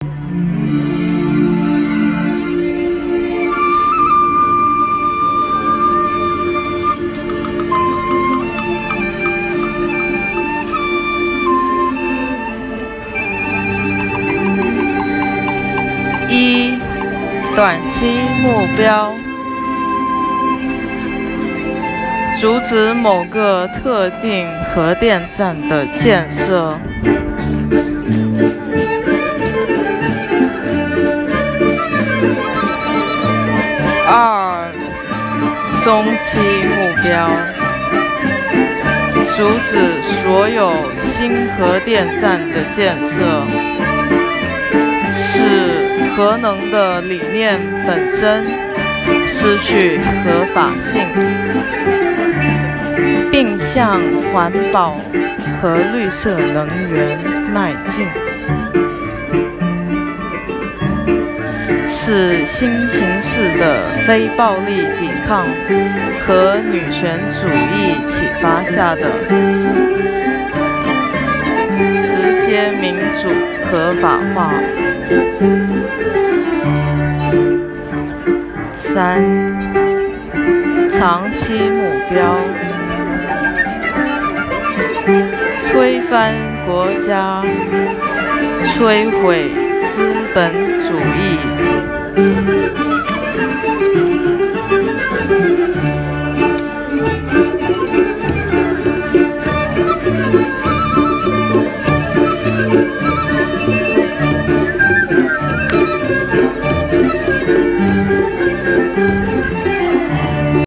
– Take out your phone and open the recording app. Press the recording button and bring the mic close to your mouth
-Choose the right time to blend with the music and read